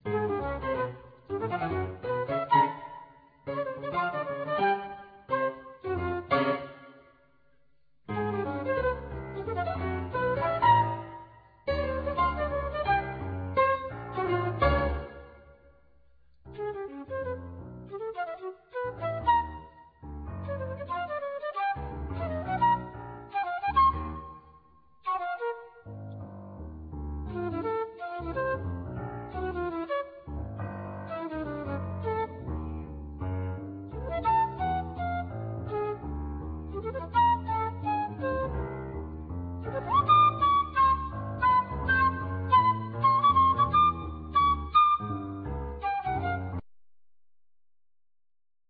Flute
Piano
Violin
Bass
Percussion